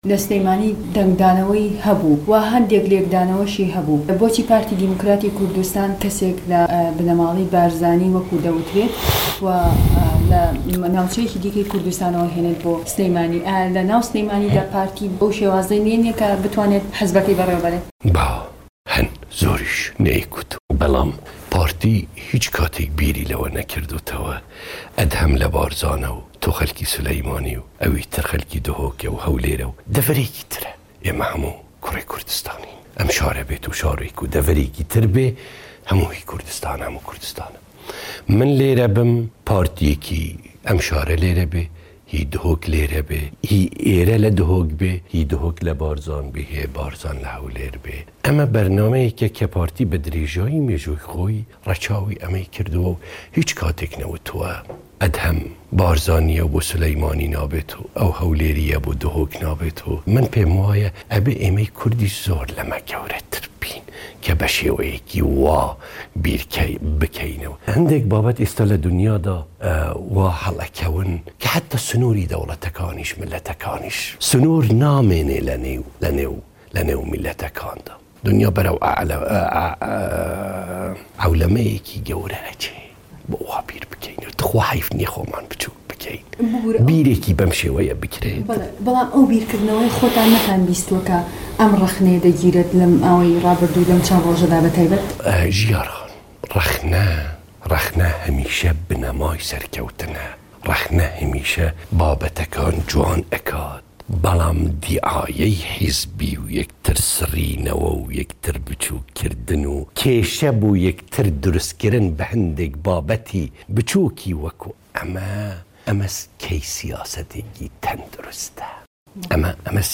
وتووێژ لەگەڵ ئەدهەم بارزانی